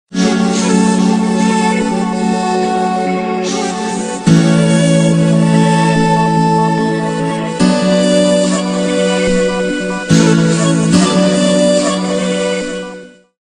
زنگ موبایل
رینگتون آرام و بیکلام